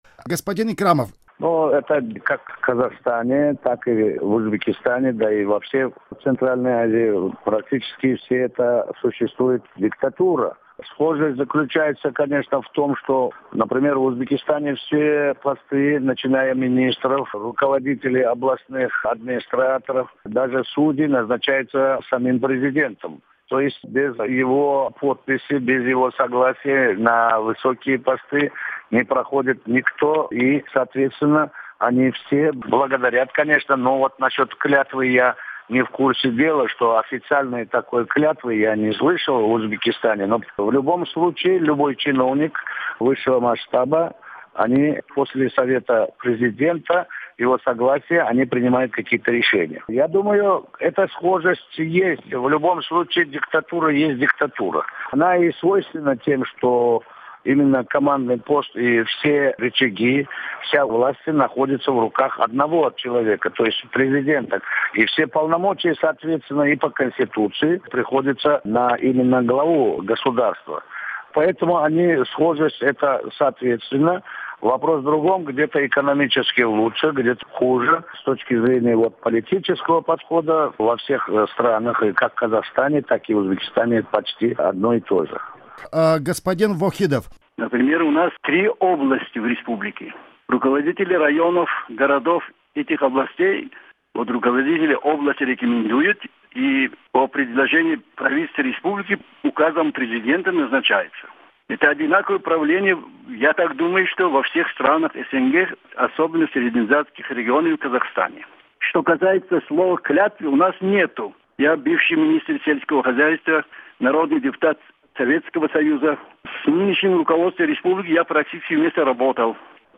Аудиозапись круглого стола